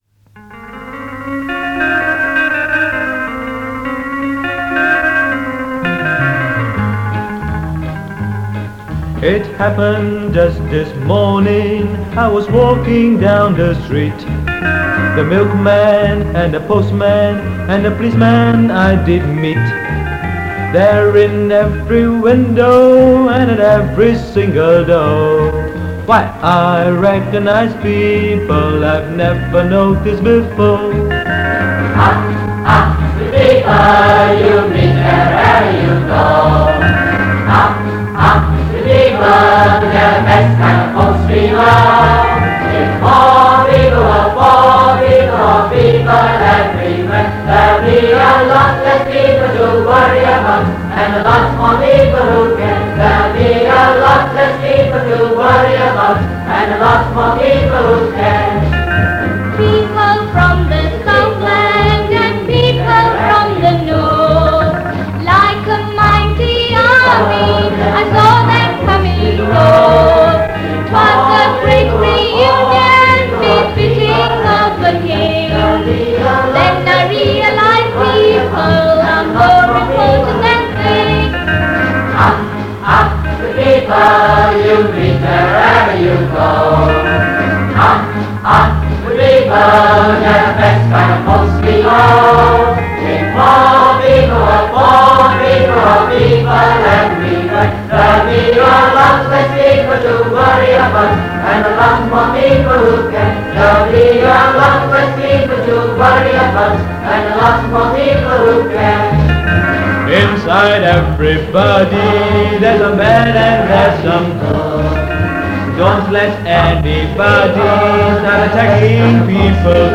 Location : Ipoh
This part features more songs from the MGS Singout of 1968.